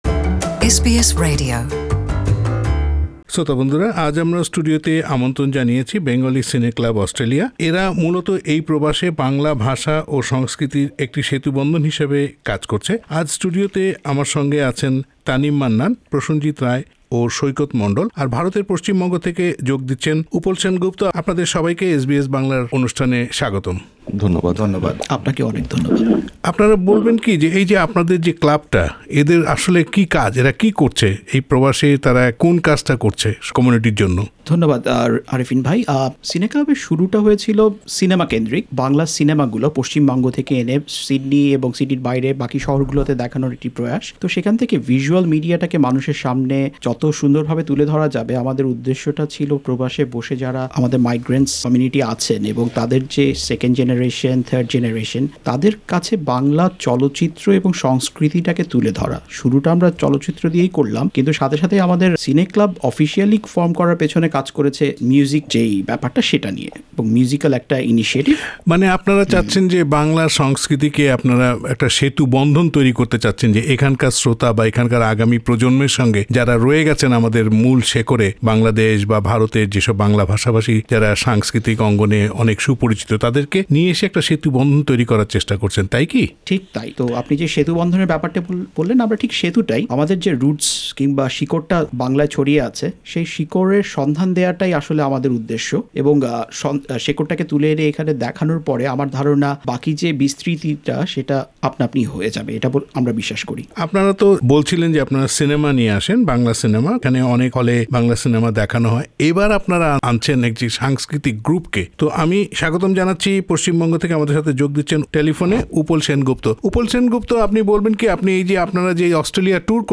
কলকাতা থেকে টেলিফোনে আরও যোগ দিয়েছেন
সাক্ষাৎকার